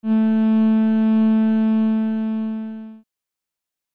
cello-f3.mp3